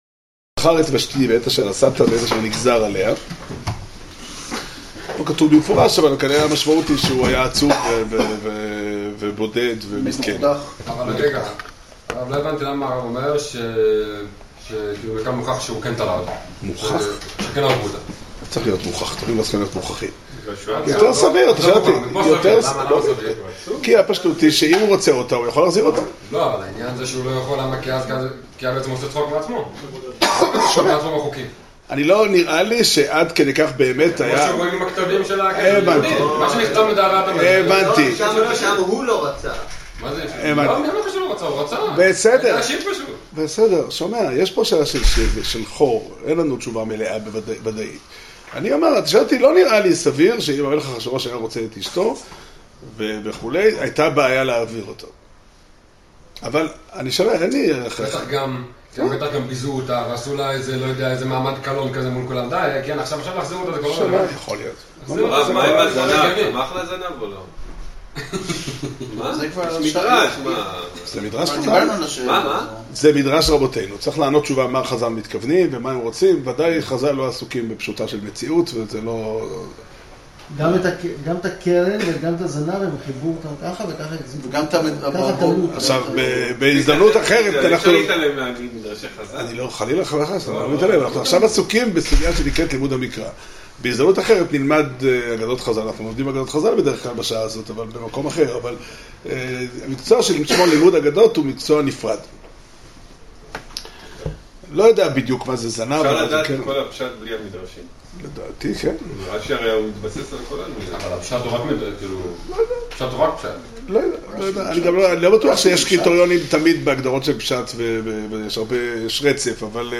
שיעור שנמסר בבית המדרש פתחי עולם בתאריך כ"ט אדר א' תשע"ט